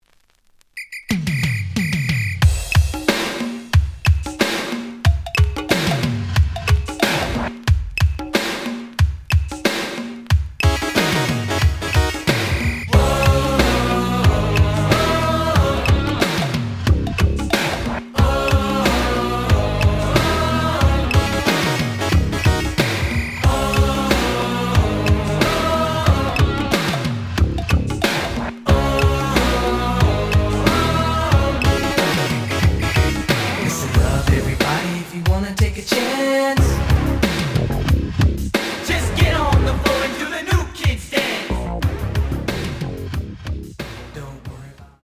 The audio sample is recorded from the actual item.
●Genre: Hip Hop / R&B
Slight damage on both side labels. Plays good.)